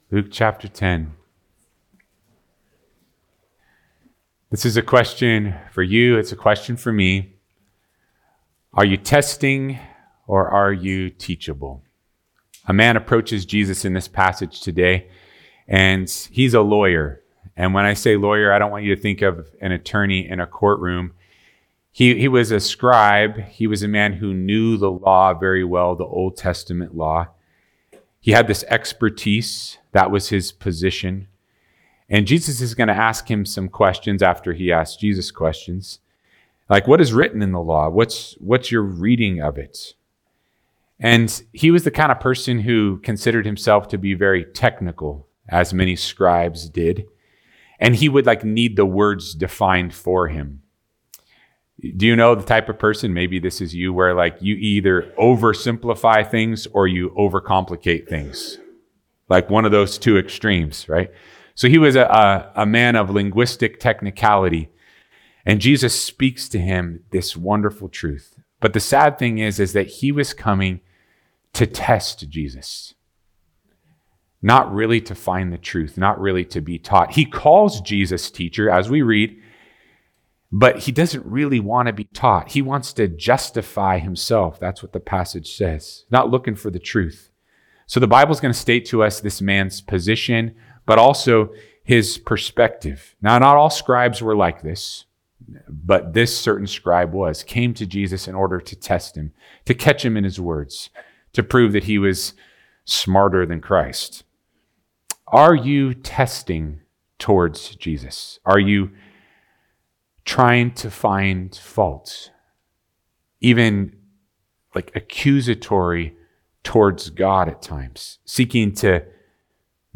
Biblical Teaching from Calvary Chapel of the Foothills in Browns Valley, CA